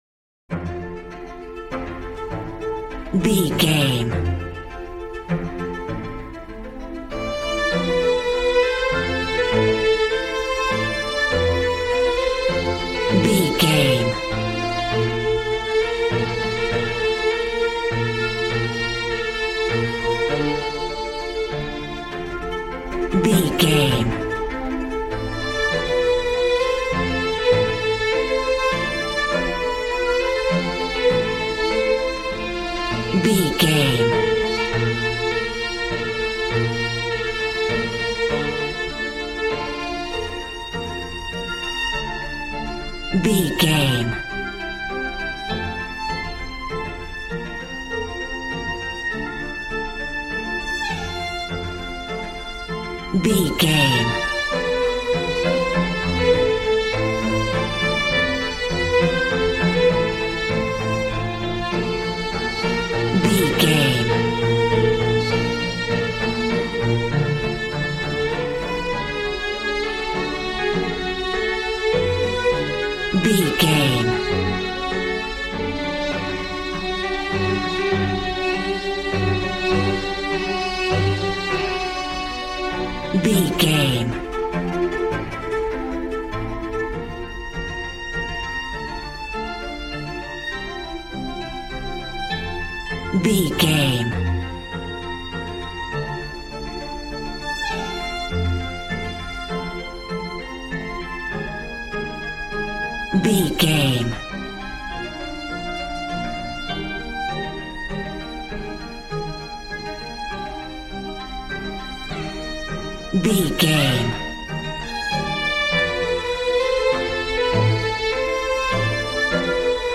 Regal and romantic, a classy piece of classical music.
Aeolian/Minor
regal
cello
violin
brass